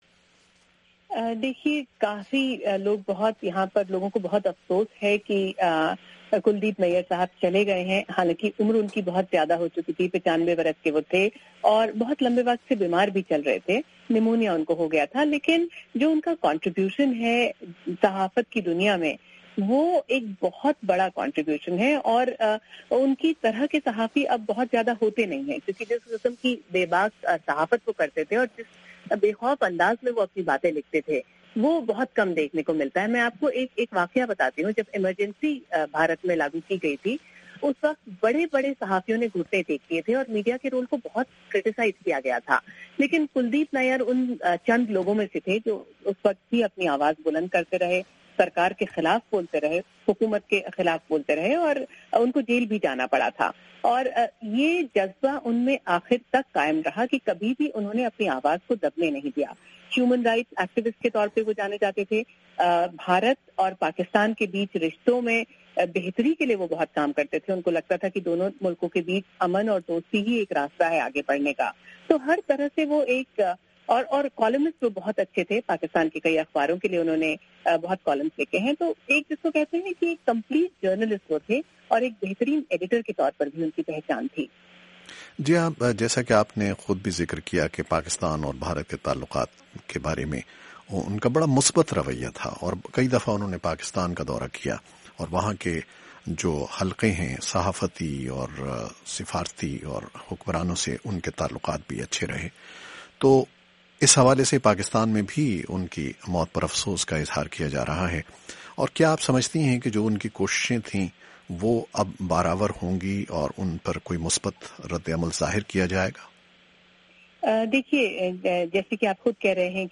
by وائس آف امریکہ